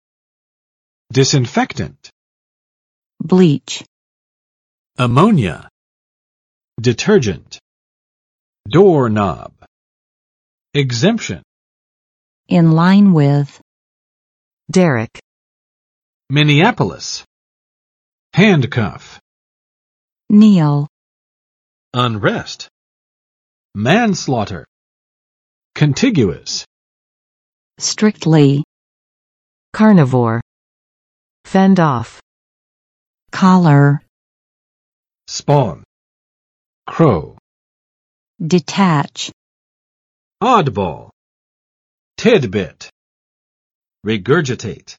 [͵dɪsɪnˋfɛktənt] n. 消毒剂